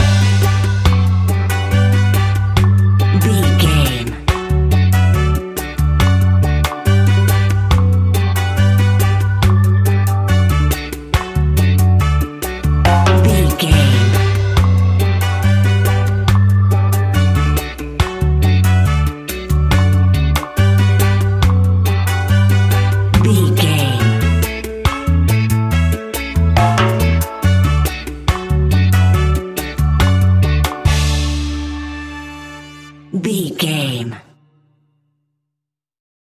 Classic reggae music with that skank bounce reggae feeling.
Ionian/Major
Slow
instrumentals
laid back
chilled
off beat
drums
skank guitar
hammond organ
percussion
horns